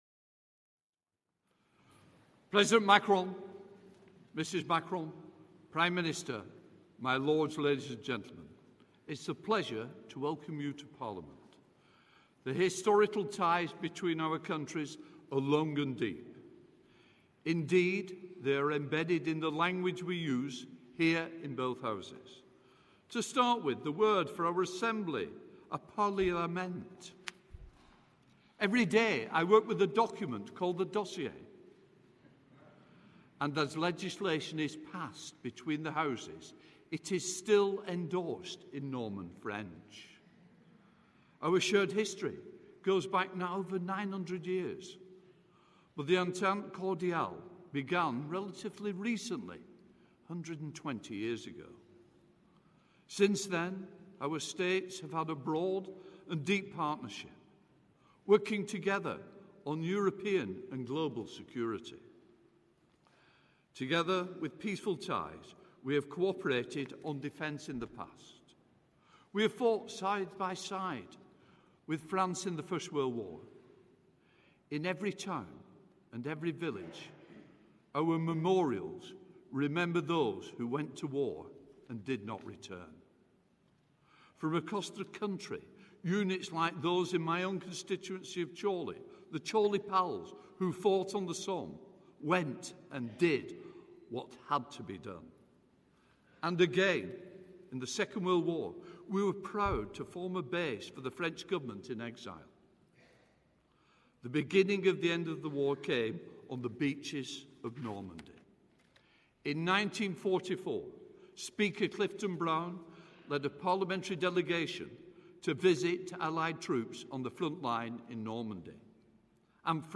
Opening Remarks for the UK Parliament Address by President Emmanuel Macron
delivered 8 July 2025, The Royal Gallery, Palace of Westminster, London